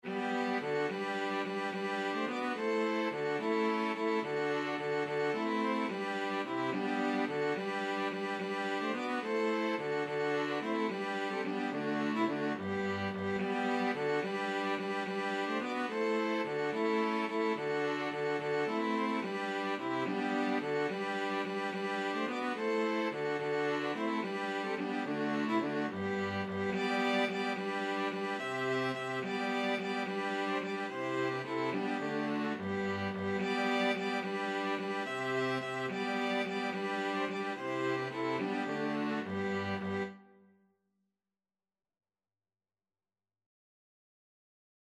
Free Sheet music for String Quartet
Violin 1Violin 2ViolaCello
G major (Sounding Pitch) (View more G major Music for String Quartet )
6/4 (View more 6/4 Music)
Classical (View more Classical String Quartet Music)